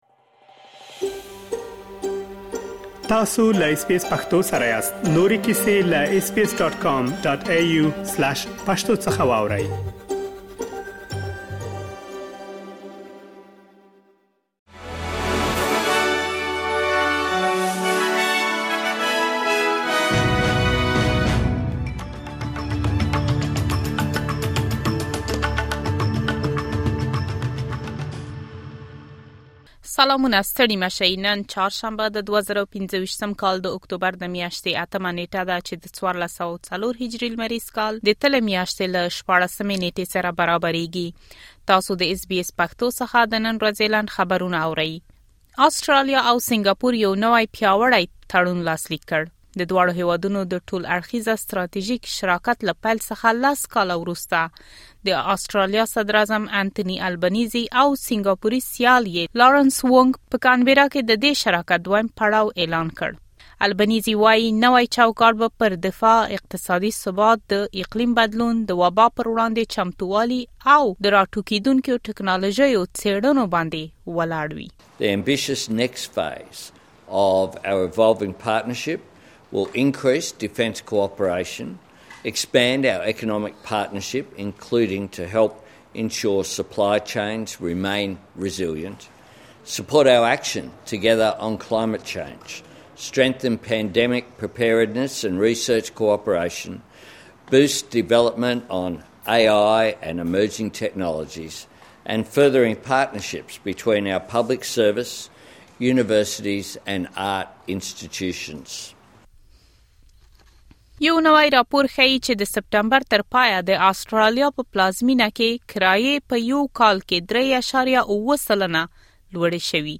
د اس بي اس پښتو د نن ورځې لنډ خبرونه|۸ اکتوبر ۲۰۲۵